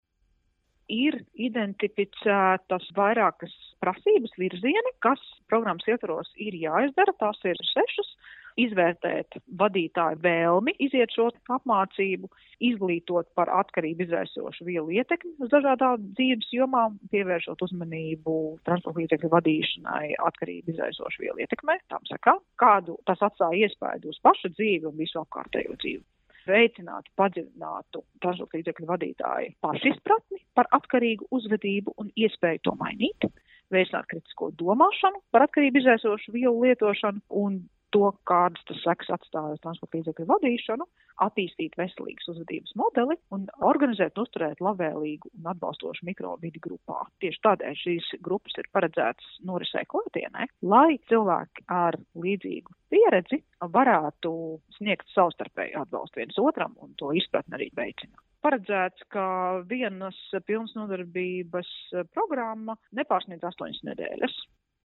RADIO SKONTO Ziņās par gaidāmo uzvedības korekcijas programmas ieviešanu apreibinošo vielu ietekmē braukušiem auto vadītājiem